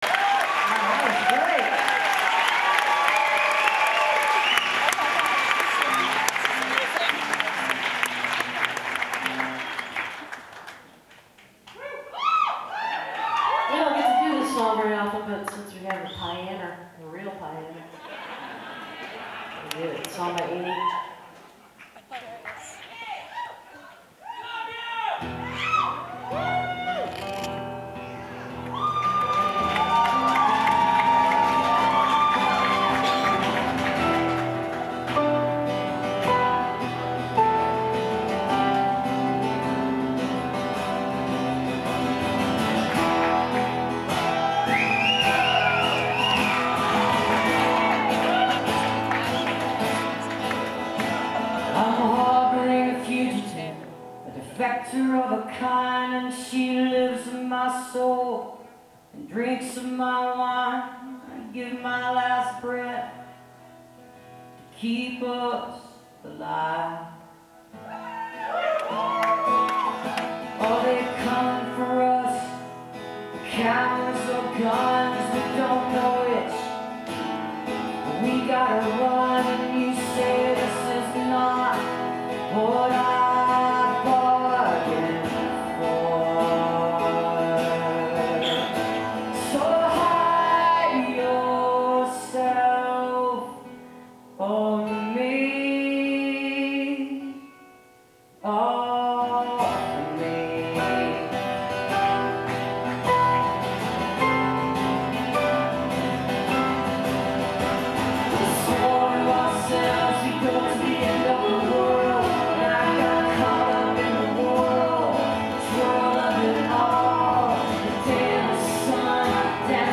zankel hall - carnegie (acjw) - new york, new york